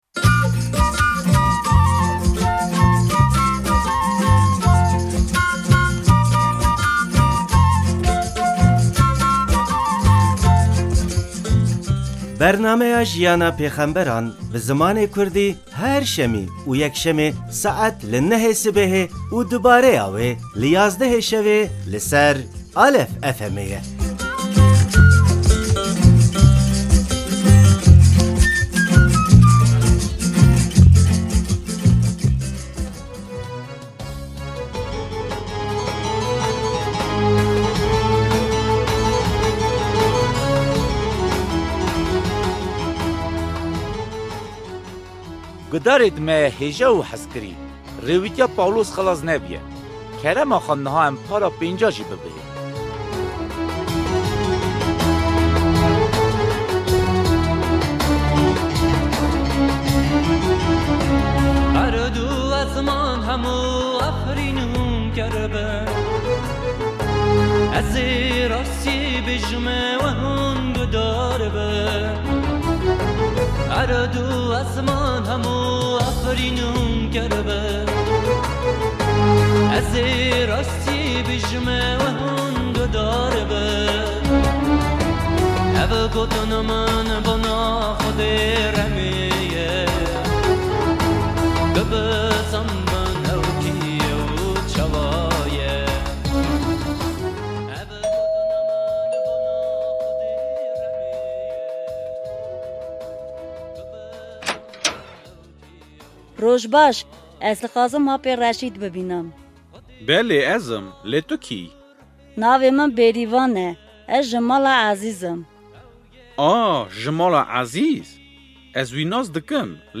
Our radio theater, which tells about the life of the prophets, was performed in Kurdish.